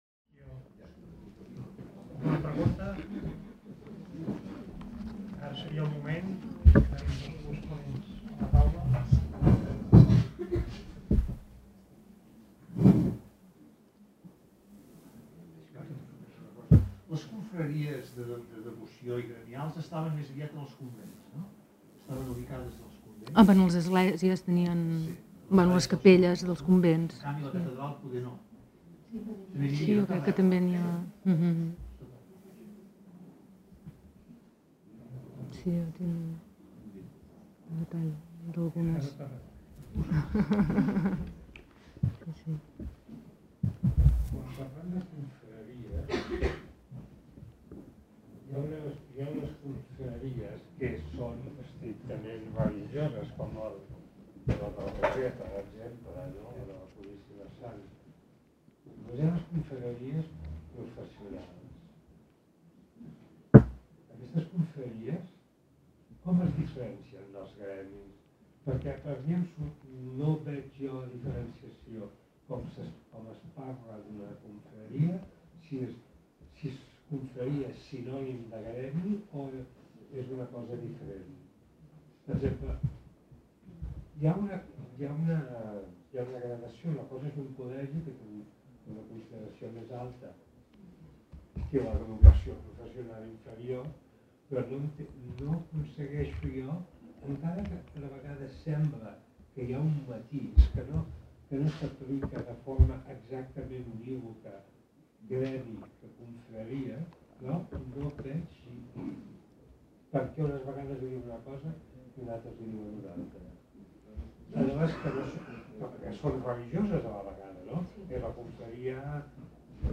Debat i torn de preguntes 3